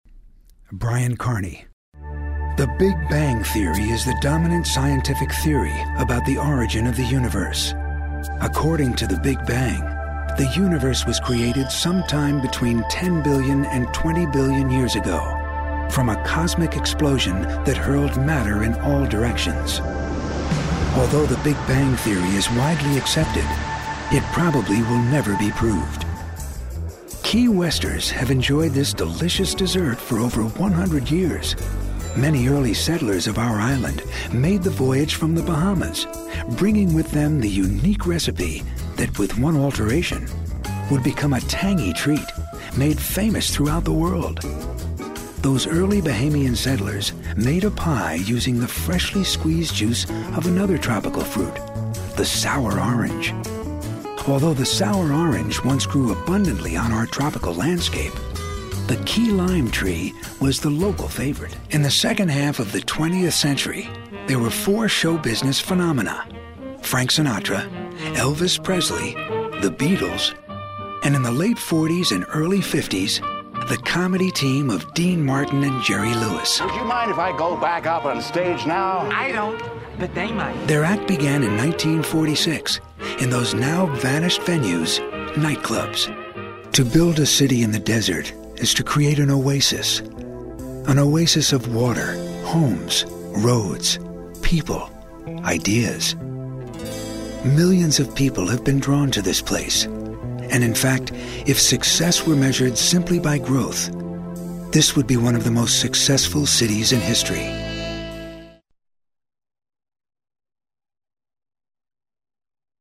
Male VOs
Listen/Download – Narration